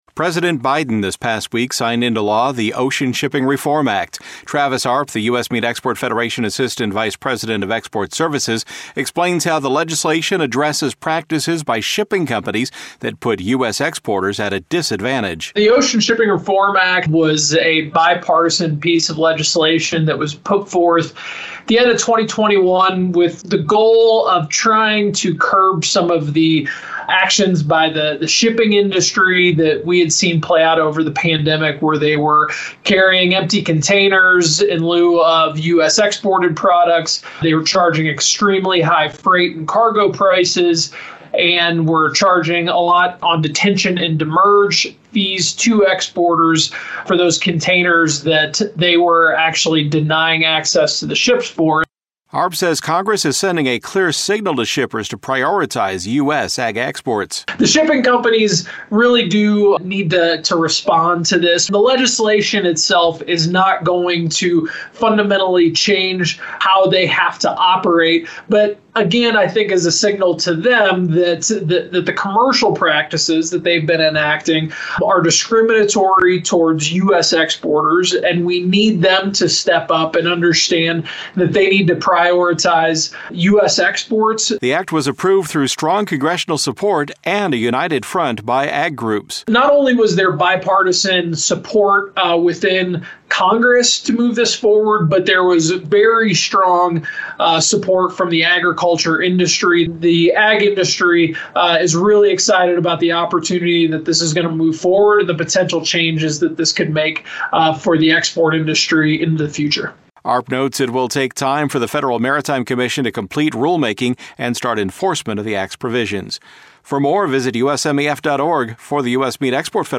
explains in this USMEF report that the new law will help improve the ocean shipping services available to exporters of U.S. agricultural products, including red meat.